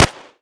pistol Fire.wav